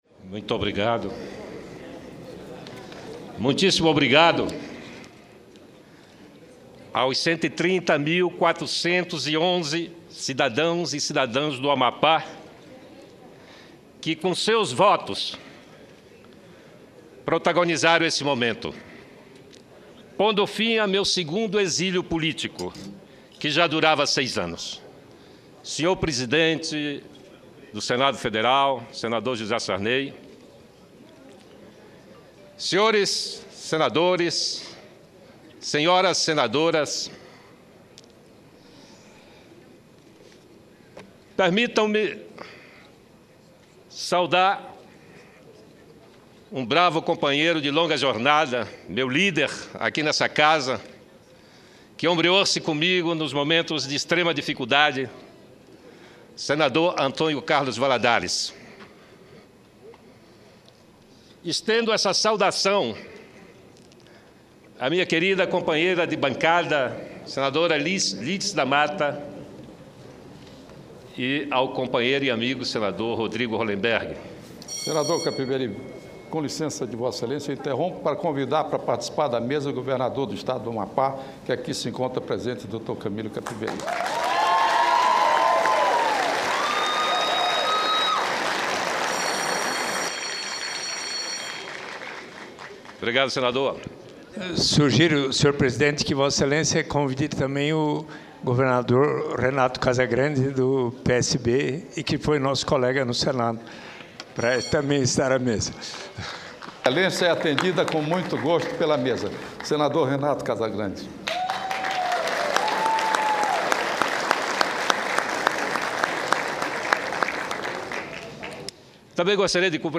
João Capiberibe faz pronunciamento de posse no Senado
Tópicos: Pronunciamento